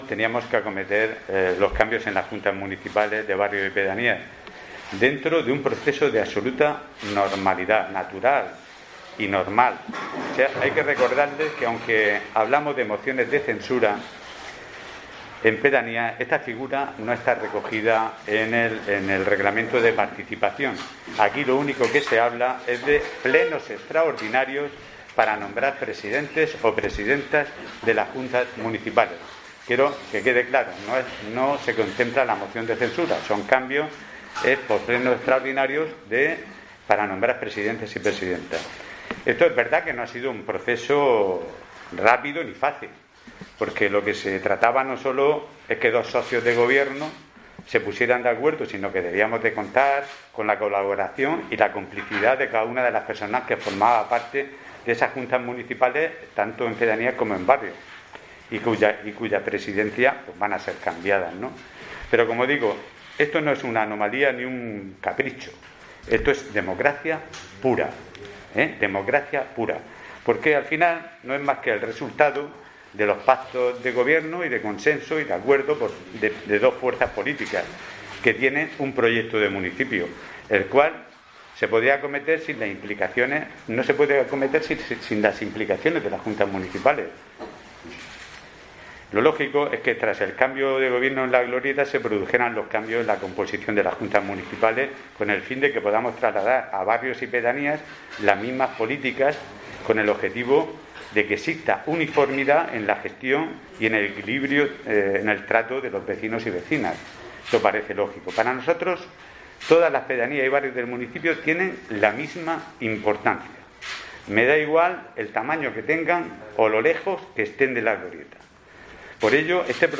José Antonio Serrano, alcalde de Murcia
Así lo han anunciado este miércoles en rueda de prensa el alcalde, José Antonio Serrano, y el vicealcalde, Mario Gómez, quienes han coincidido en señalar que el objetivo es que el proceso se lleve a cabo con "naturalidad y normalidad", con el fin de "poner en práctica una administración impecable y transparente".